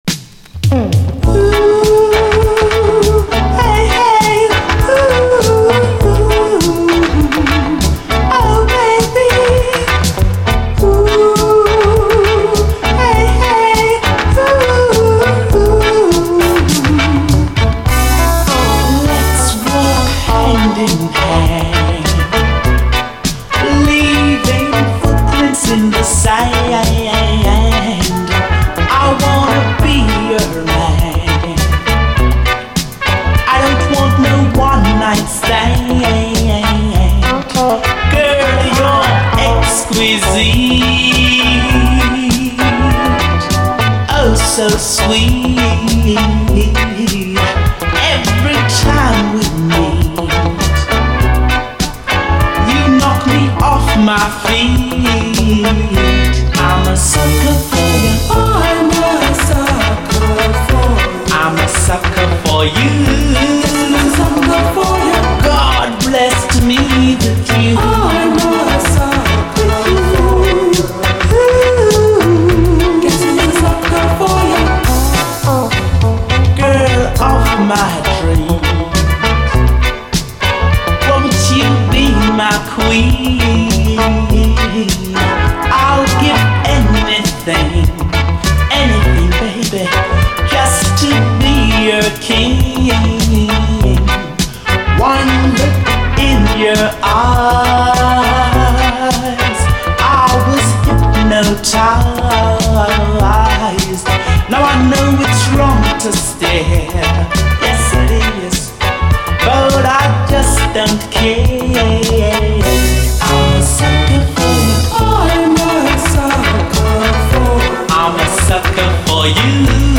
REGGAE
持ち味のコミカルな軽妙さが発揮されたゴキゲンなラヴァーズ・チューン！